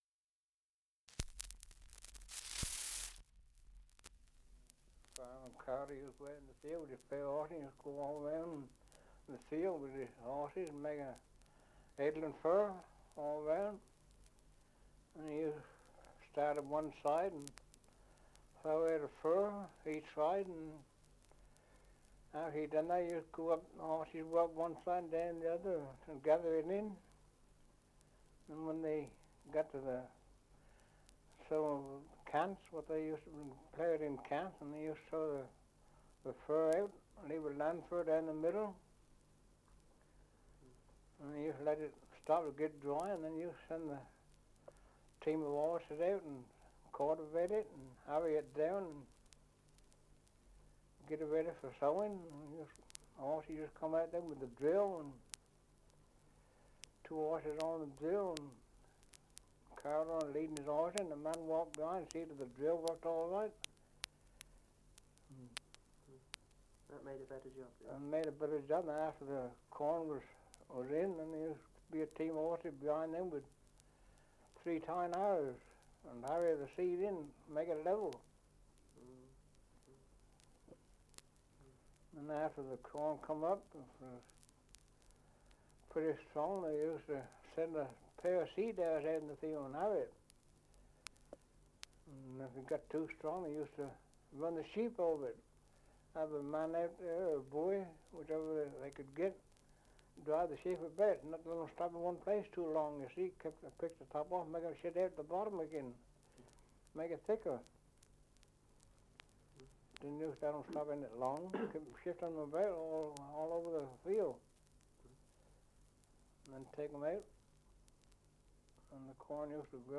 Survey of English Dialects recording in Warnham, Sussex
78 r.p.m., cellulose nitrate on aluminium